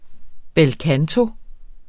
Udtale [ bεlˈkanto ]